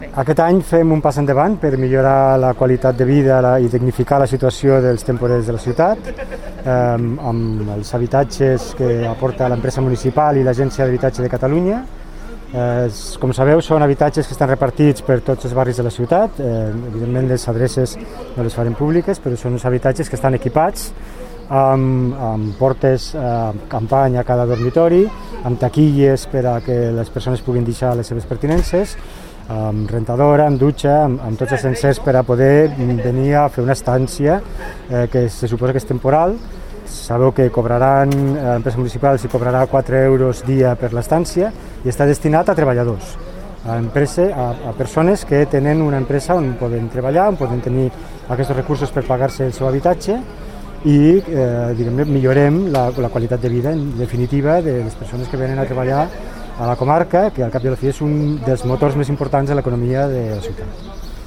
tall-de-veu-del-tinent-dalcalde-sergi-talamonte-sobre-els-pisos-per-a-persones-temporeres